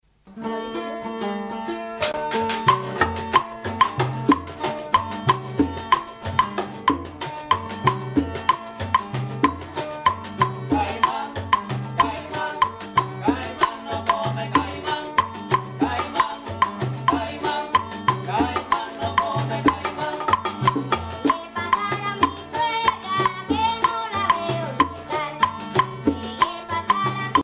chanté par Parranda de Los Hermanos Sobrino
Pièces musicales tirées de la Parranda Tipica Espirituana, Sancti Spiritus, Cuba
Pièce musicale inédite